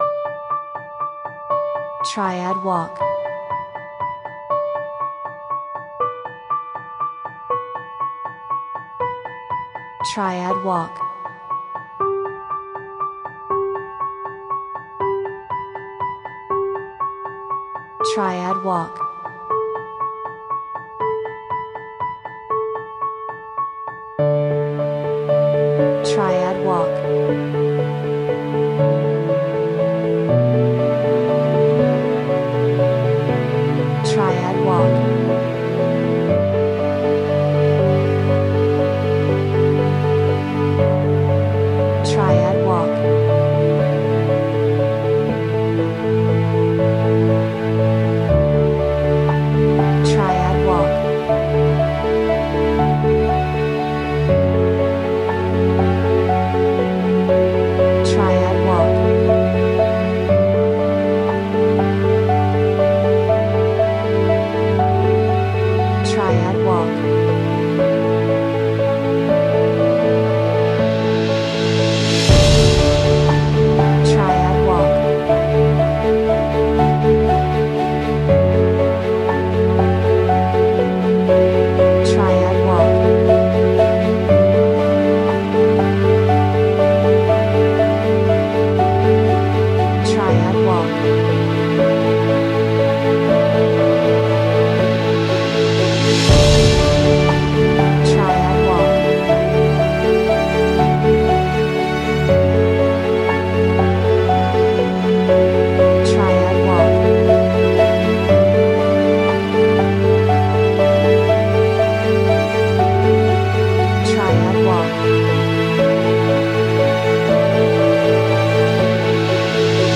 ストリングス